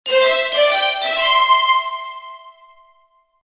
Game Level Up Sound Effect
The audio cue plays when a player advances to the next level in a video game, signaling progress, achievement, or success.
Genres: Sound Effects
Game-level-up-sound-effect.mp3